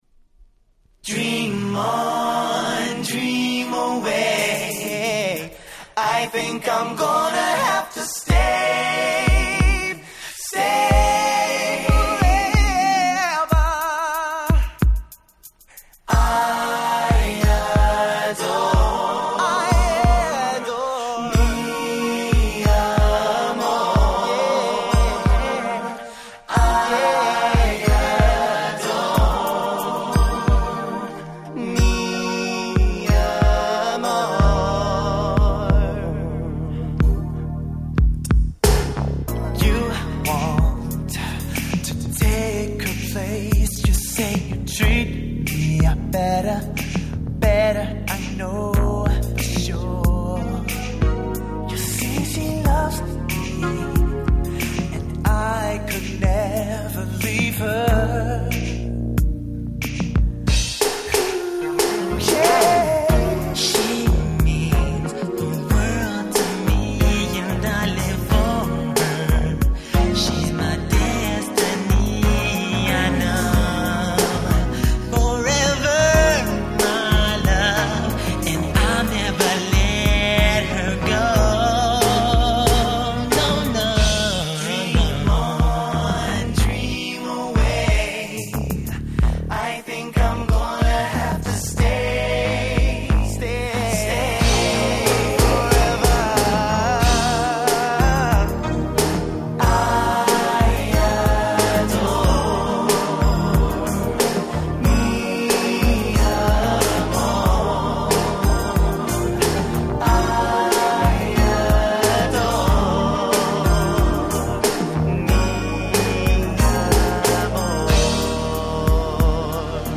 91' Super Hit Slow Jam !!
イントロから相変わらず最高なコーラスワークで幕を開け、そのままグイグイ引き込まれていく様な最高のSlow Jam !!